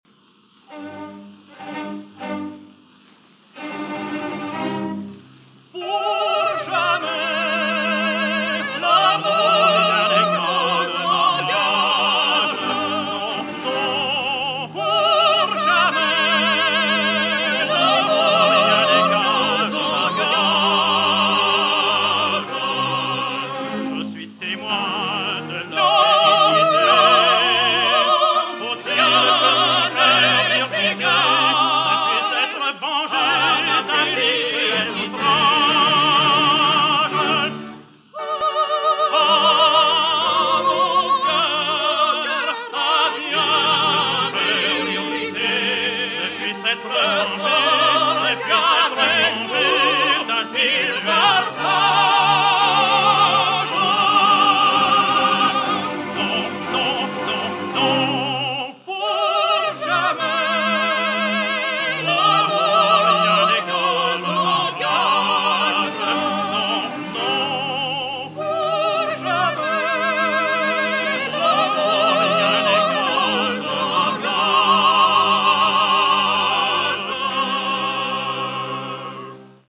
Pour jamais l'amour nous engage, with Camille Maurane and Irène Joachim